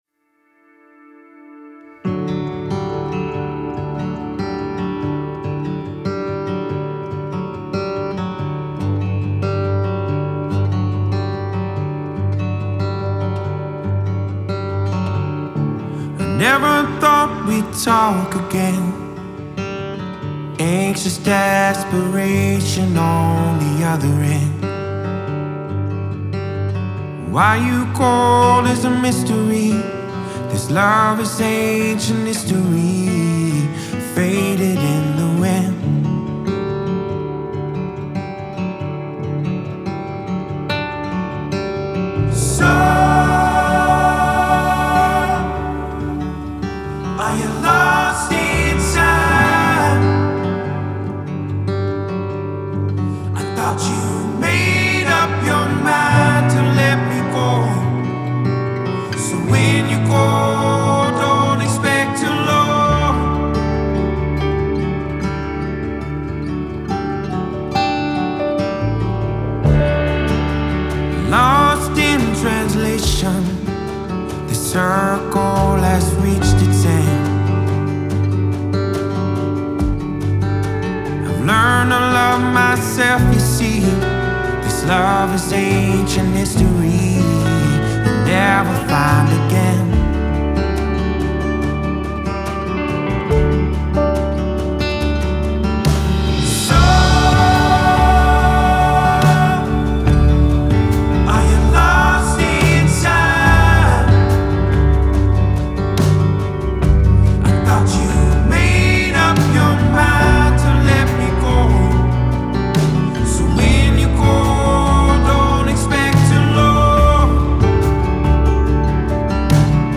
conhecido por sua voz poderosa e versatilidade